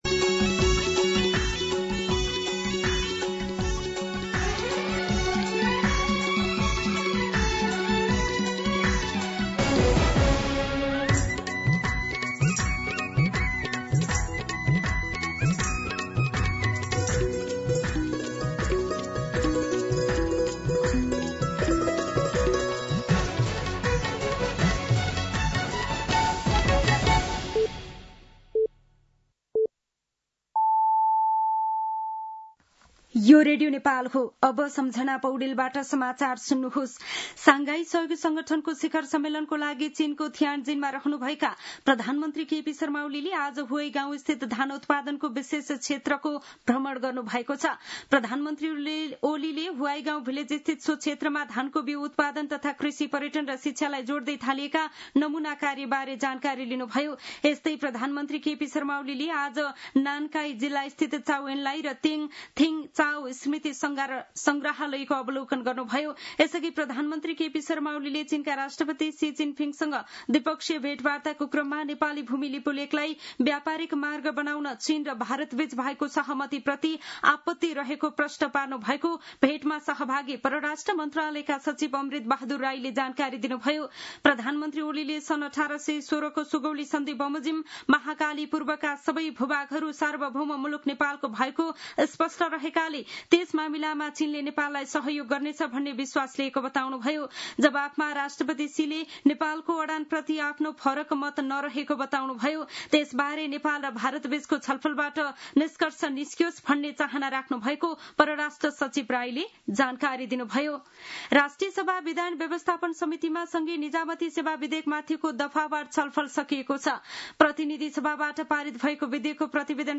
दिउँसो १ बजेको नेपाली समाचार : १८ पुष , २०२६